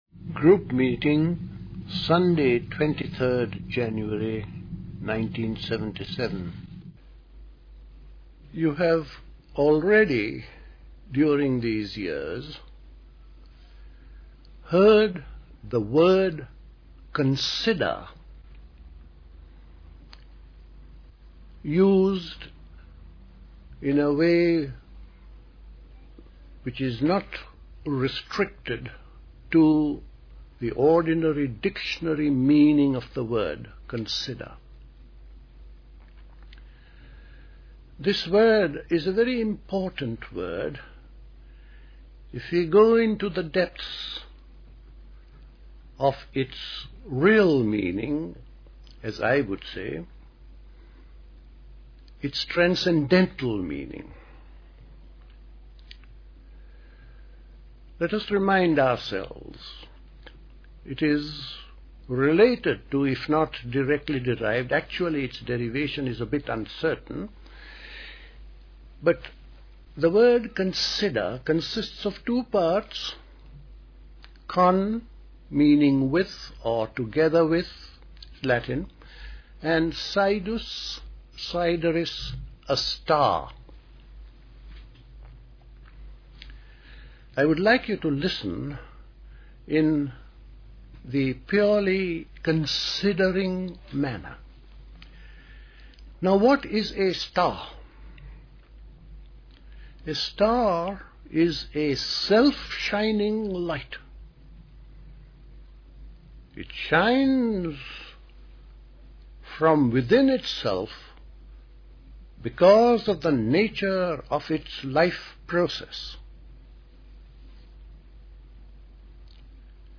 A talk
at Dilkusha, Forest Hill, London on 23rd January 1977